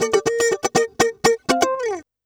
120FUNKY05.wav